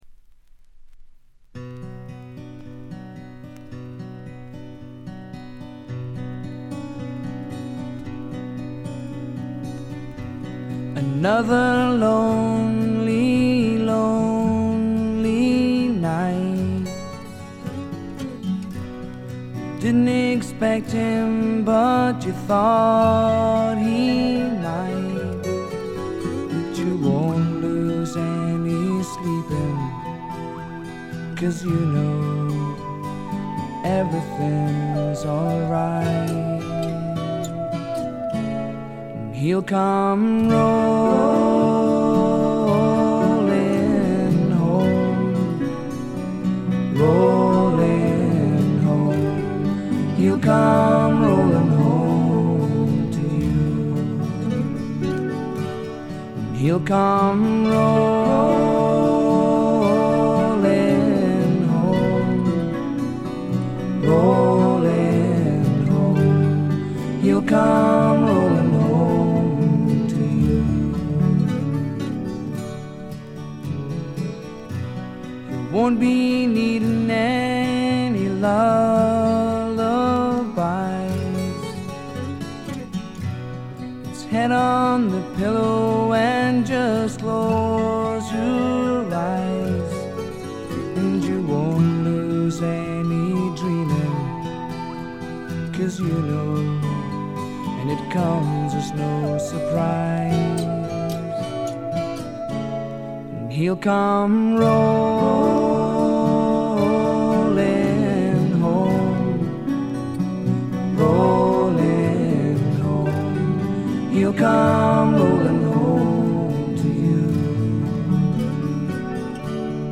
ほとんどノイズ感無し。
試聴曲は最大の魅力である哀愁味あふれる沁みる歌声に焦点を当てましたが、この人は楽器の腕前も一級品です。
試聴曲は現品からの取り込み音源です。